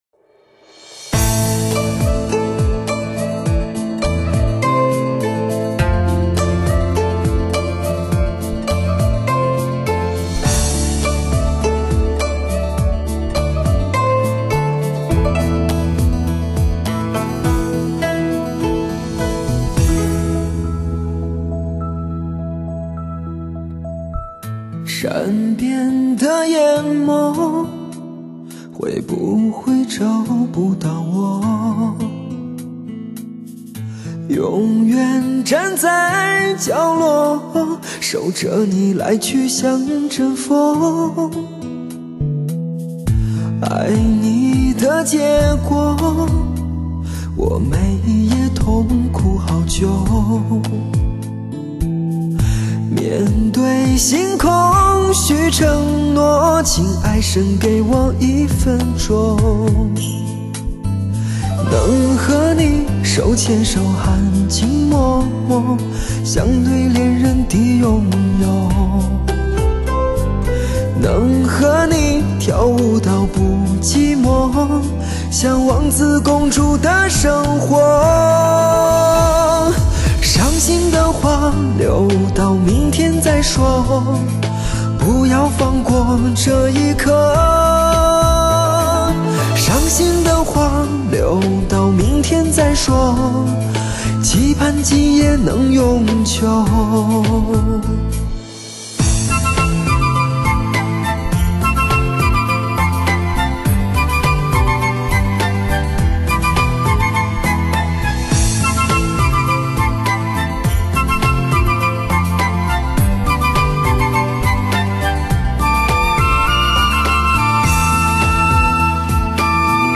360环绕3D音效